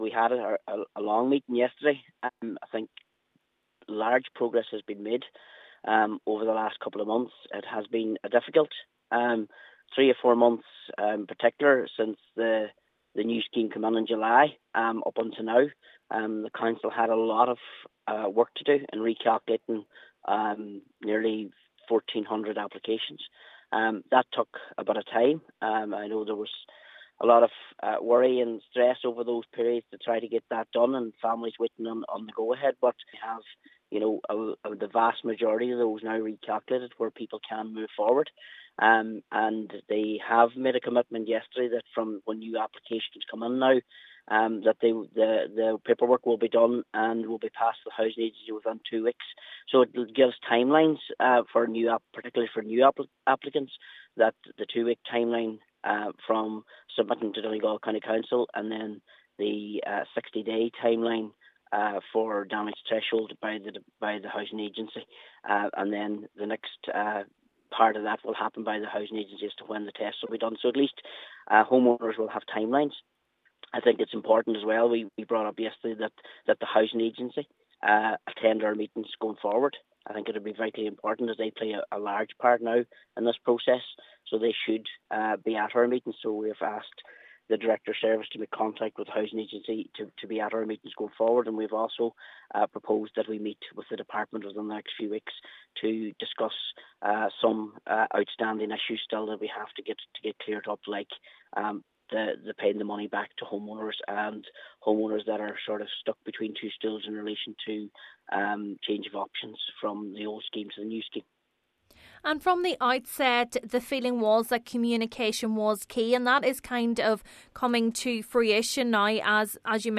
Chair of the Committee, Councillor Martin McDermott says having a timeline to work towards is important for affected homeowners engaged with the scheme: